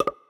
Special Pop (9).wav